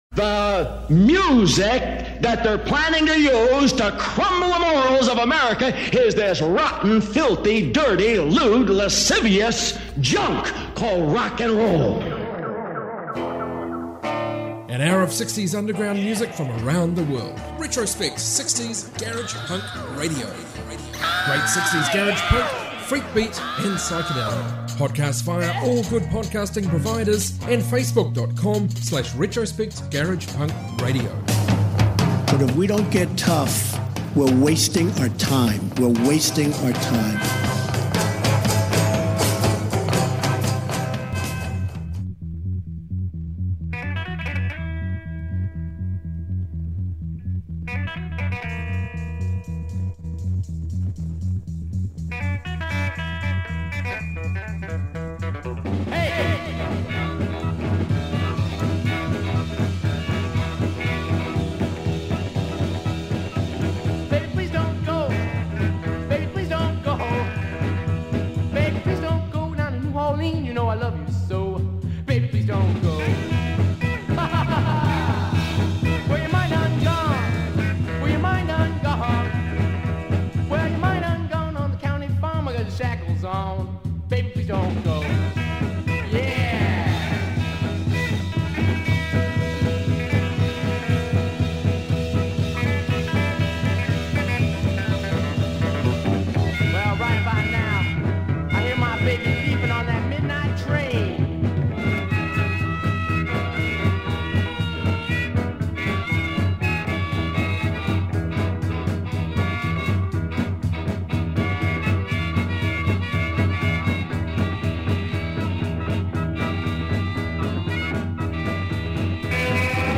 60s global garage music